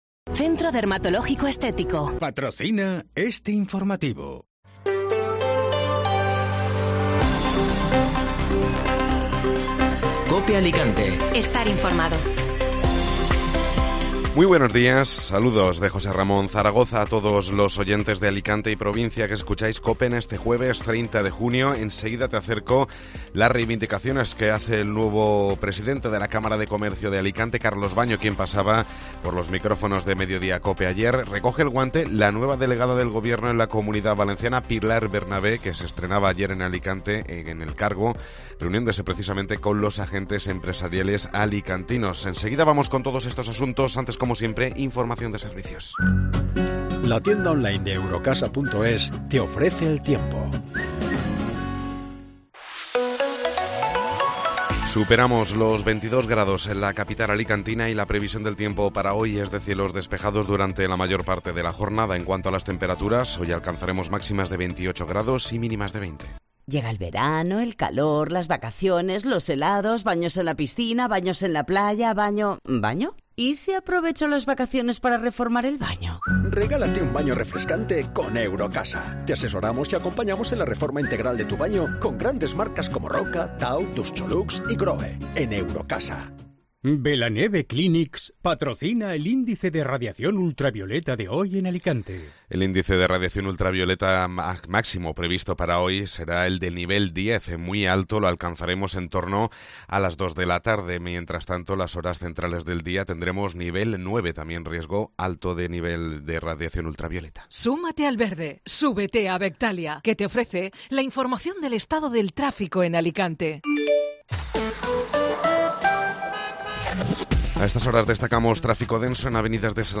Informativo Matinal (Jueves 30 de Junio)